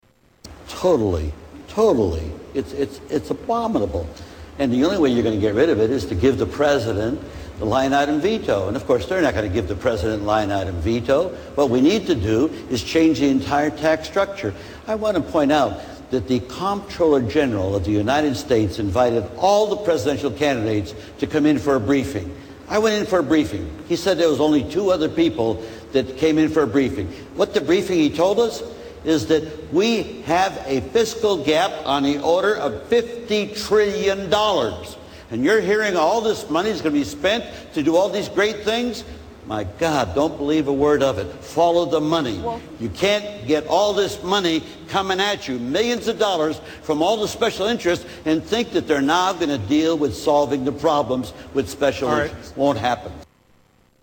Briefing
Tags: Political Mike Gravel Presidential Candidate Democratic Mike Gravel Speeches